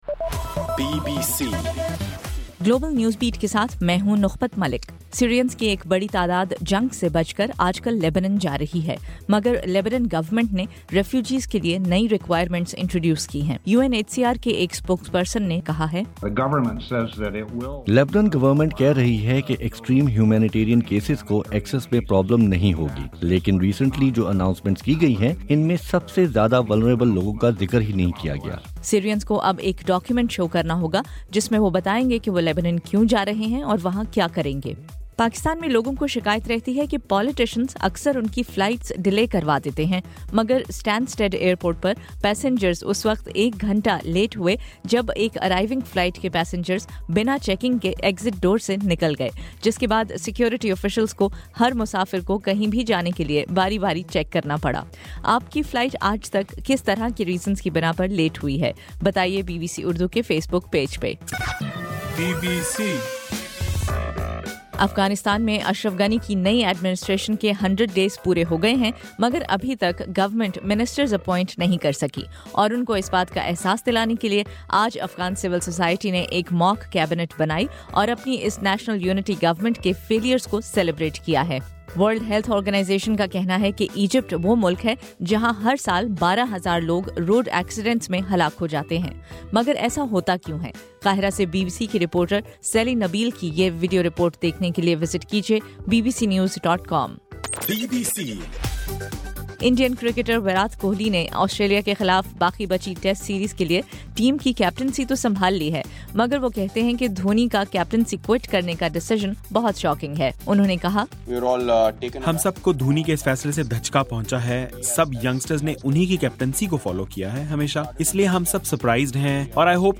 جنوری 5: رات 9 بجے کا گلوبل نیوز بیٹ بُلیٹن